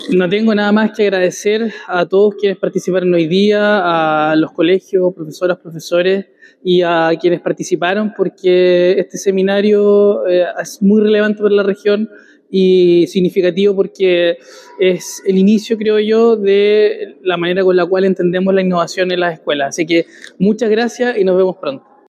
cuna-seremi-encuentro-innovacion.mp3